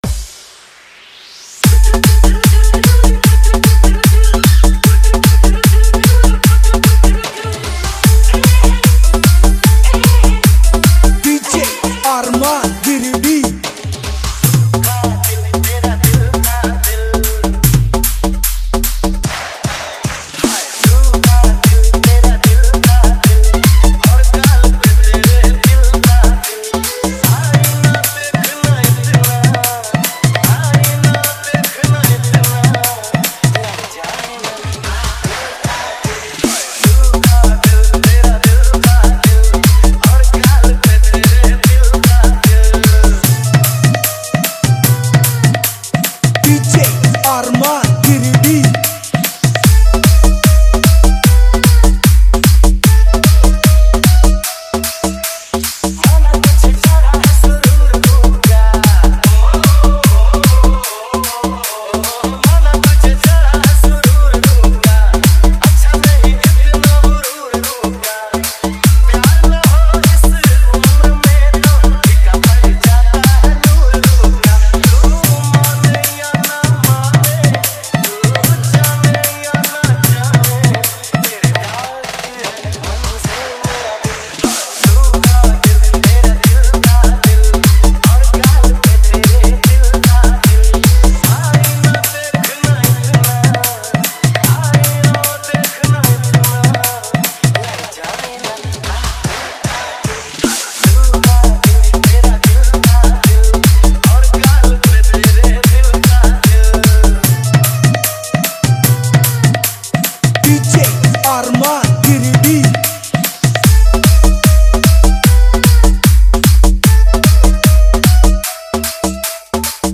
Category : Old Is Gold Remix Song